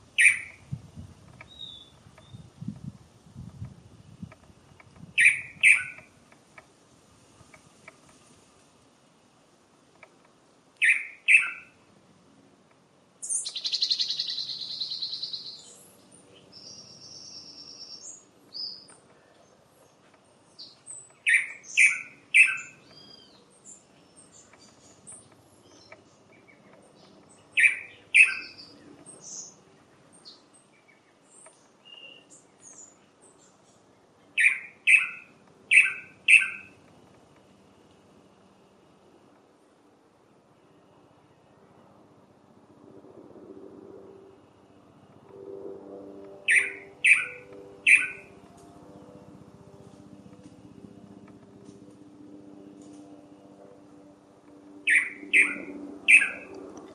Blue Manakin (Chiroxiphia caudata)
Sex: Male
Location or protected area: Parque Nacional Iguazú
Condition: Wild
Certainty: Filmed, Recorded vocal